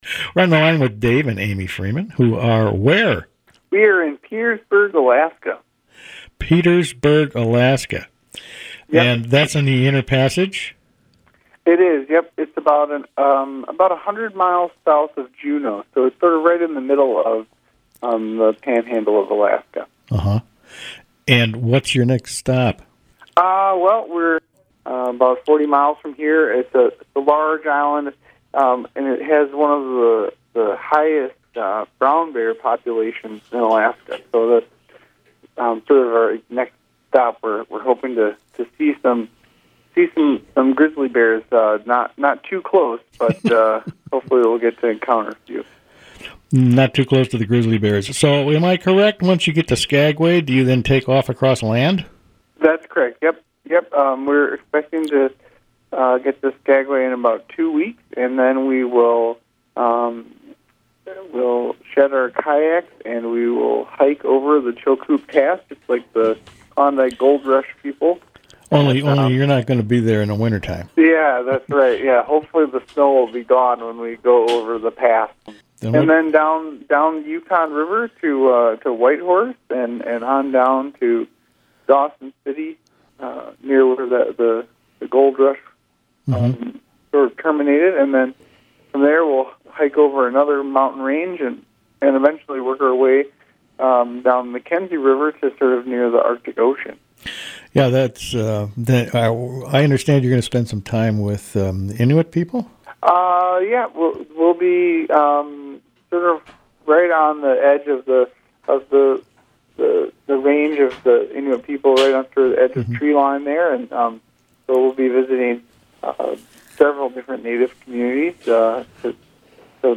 North American Odyssey at the Inland Passage | WTIP North Shore Community Radio, Cook County, Minnesota